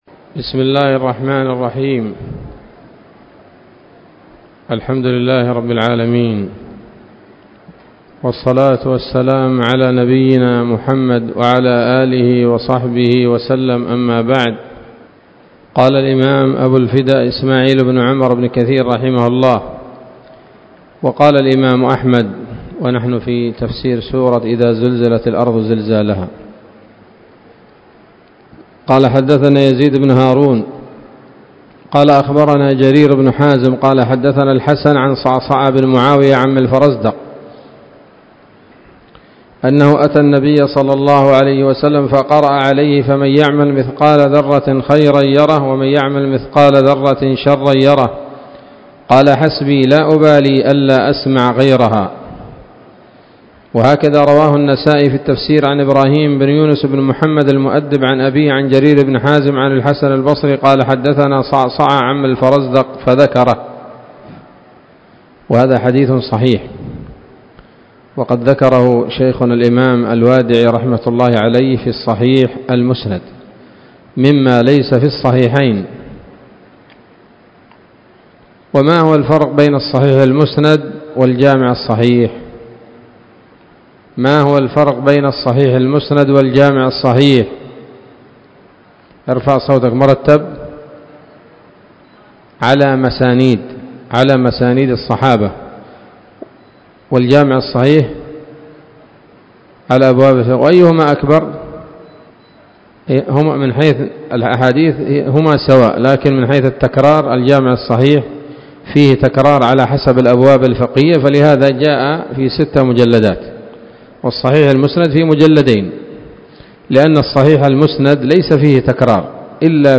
الدرس الثاني وهو الأخير من سورة الزلزلة من تفسير ابن كثير رحمه الله تعالى